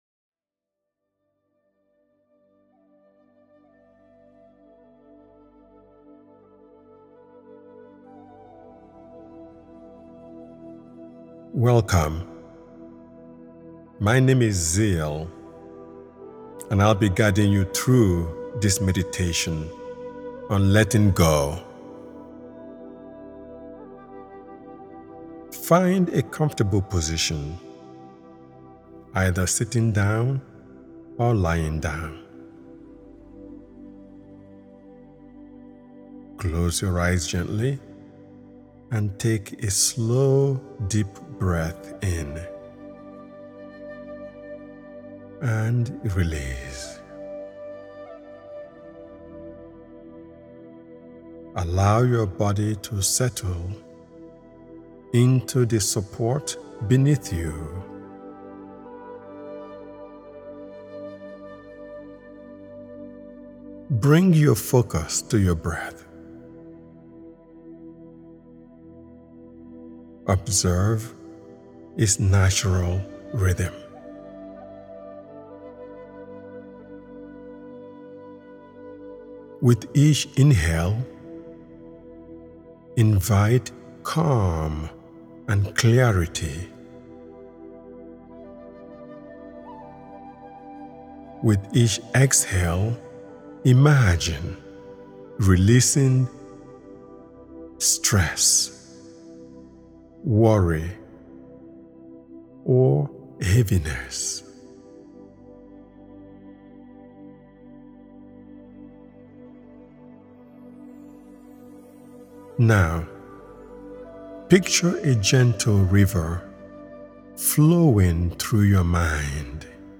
It is also a beautiful practice before sleep, as the soothing imagery and soft pacing help settle the mind into a quieter, more relaxed state. The gentle rhythm of the words and the steady flow of the breath work guide the nervous system into restfulness, making it easier to drift into peaceful, restorative sleep.
The meditation uses calm, reassuring language to help you explore this space.